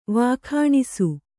♪ vākhāṇisu